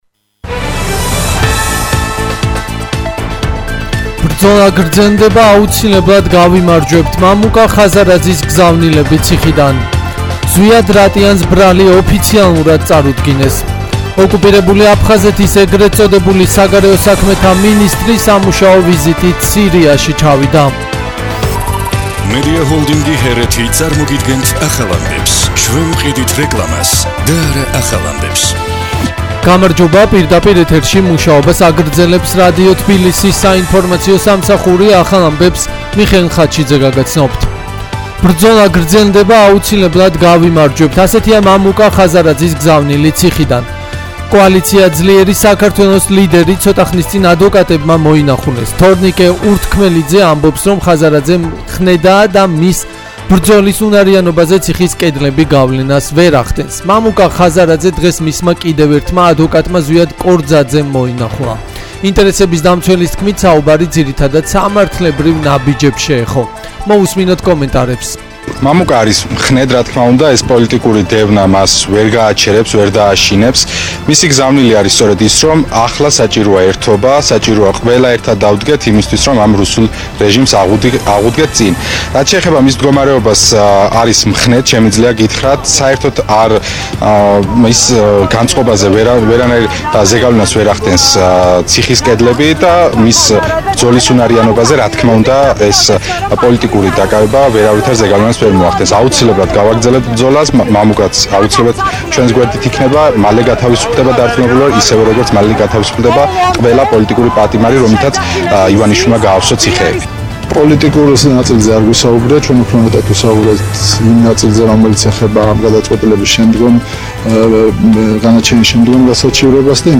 ახალი ამბები 17:00 საათზე